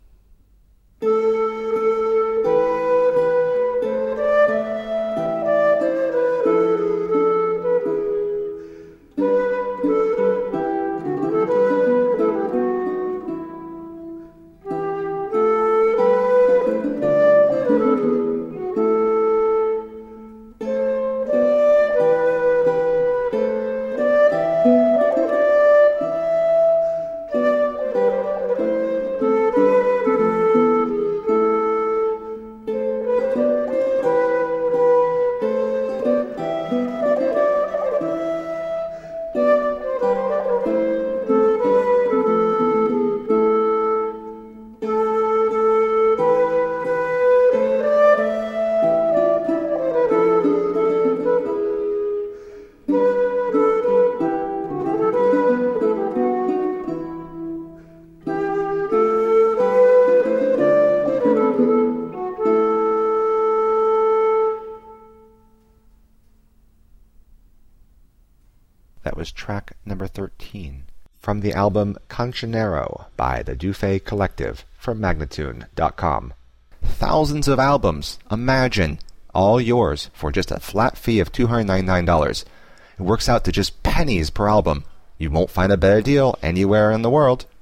Classical, Renaissance, Classical Singing
Flute, Lute, Viola da Gamba